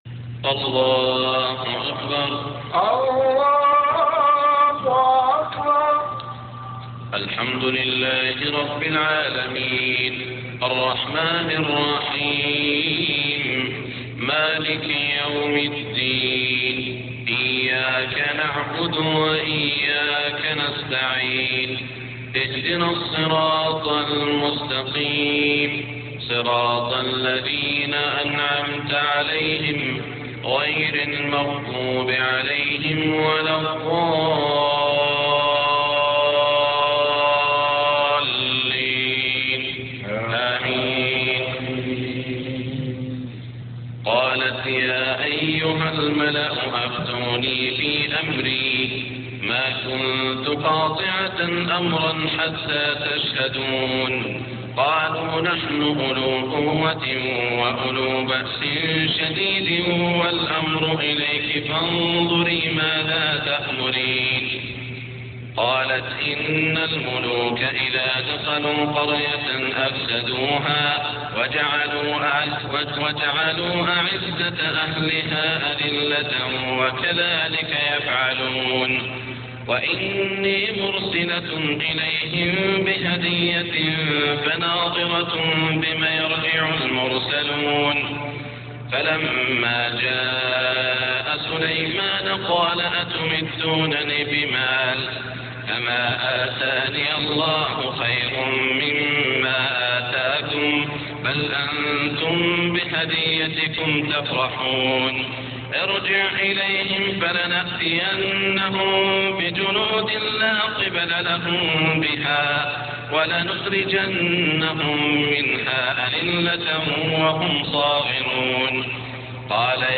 صلاة الفجر 1422هـ من سورة النمل > 1422 🕋 > الفروض - تلاوات الحرمين